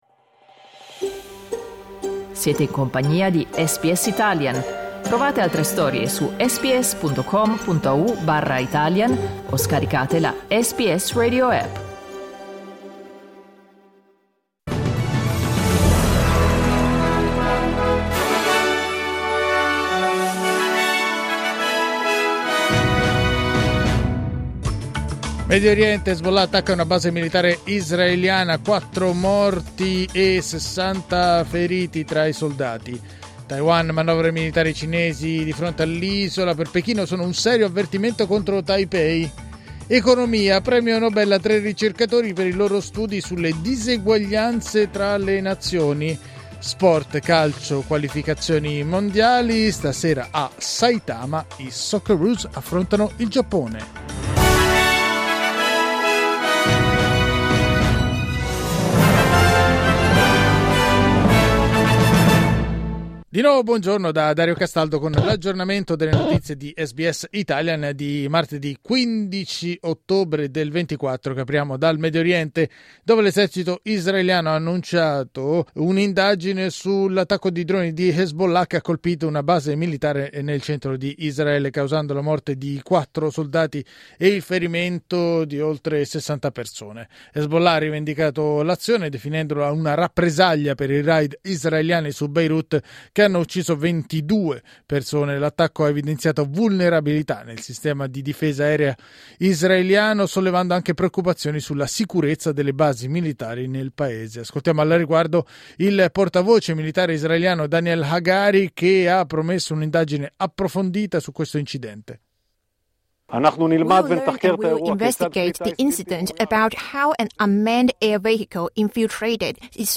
News flash martedì 15 ottobre 2024